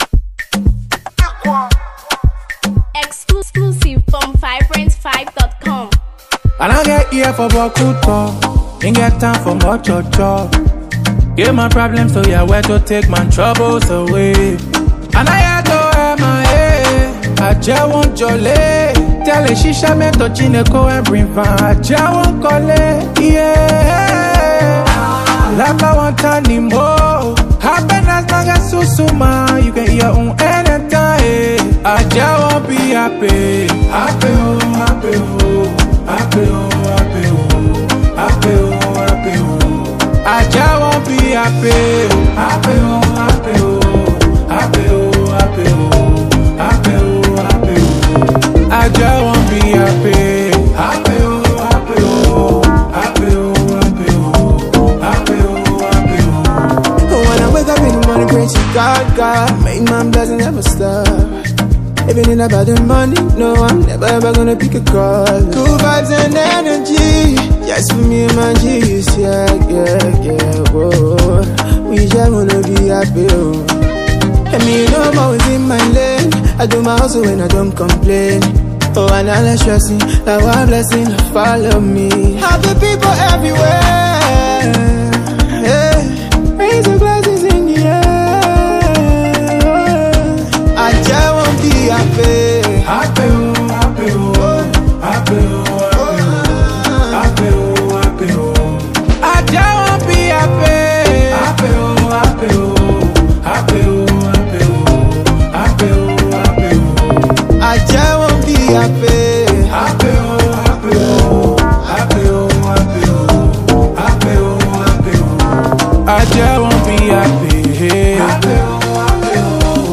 Heartfelt Anthem of Triumph and Joy